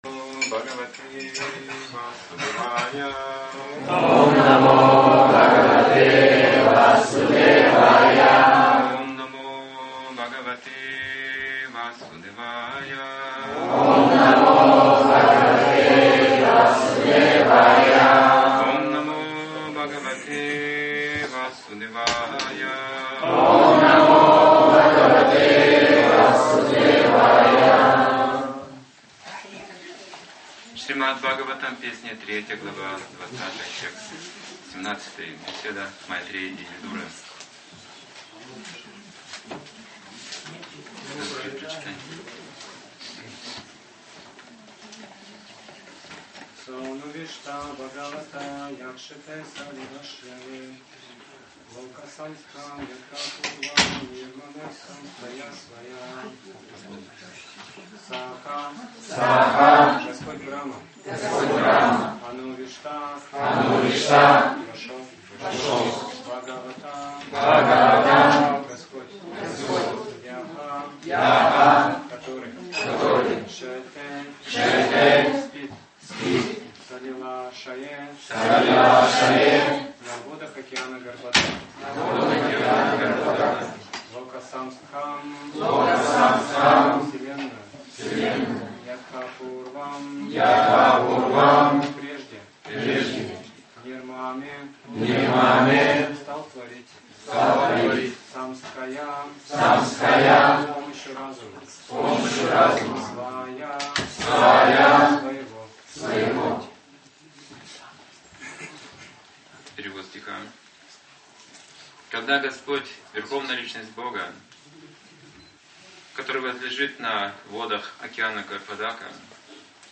Темы, затронутые в лекции
Калиновка